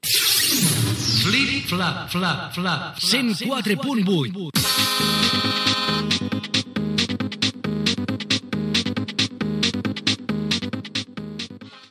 Indicatiu de l'emissora i tema musical